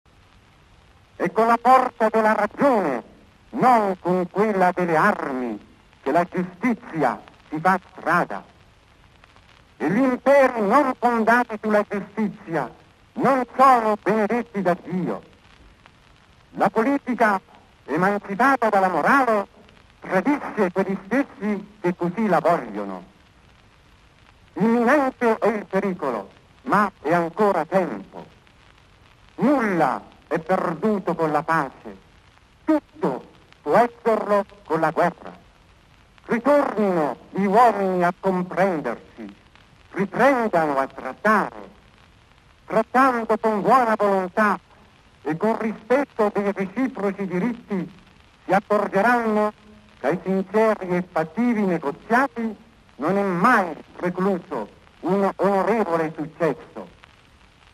A few days ago, on August 24 of that year, Pope Pius XII Address these words to rulers and peoples in a radio message